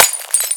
glass3.ogg